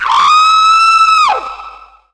Sound effects wavs
SCREAM4.WAV